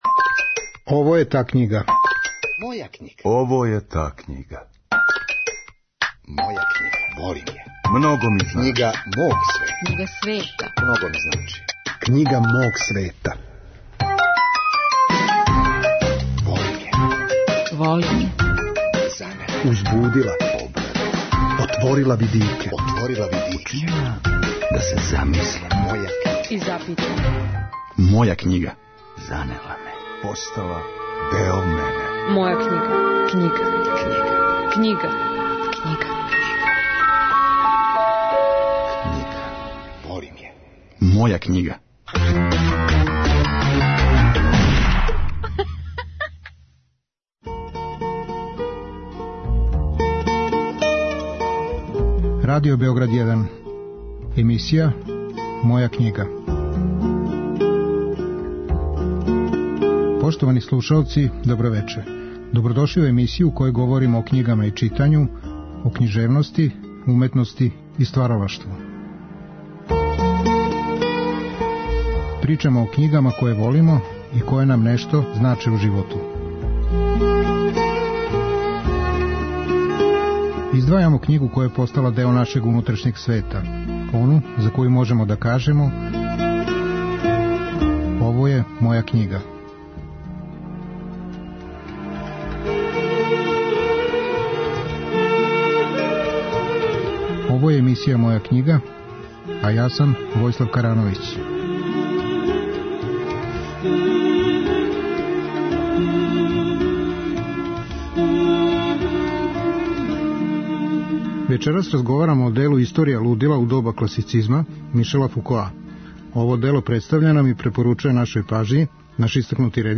У вечерашњој емисији гост је Горан Марковић, филмски и позоришни редитељ, сценариста и писац. Разговарамо о књизи 'Историја лудила у доба класицизма' Мишела Фукоа.